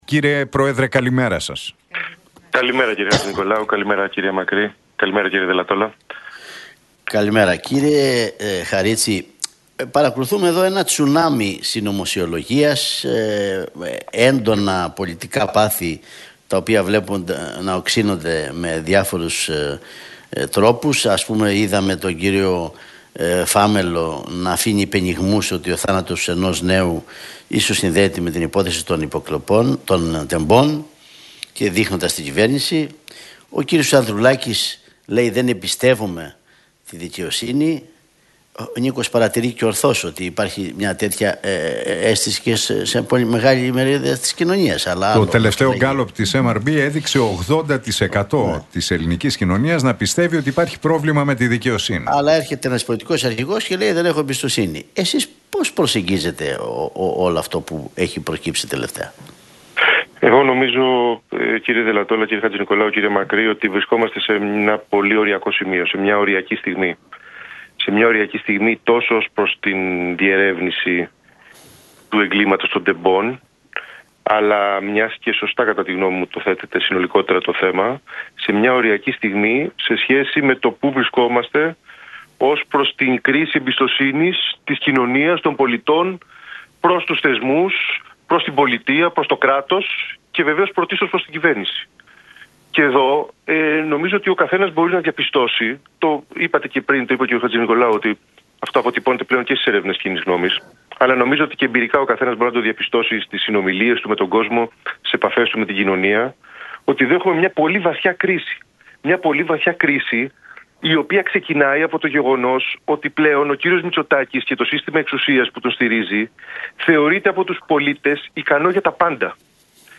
Χαρίτσης στον Realfm 97,8 για Τέμπη: Έχουμε μια καραμπινάτη περίπτωση συγκάλυψης σε πολιτικό και σε επιχειρησιακό επίπεδο - Αυτή η κυβέρνηση πρέπει να φύγει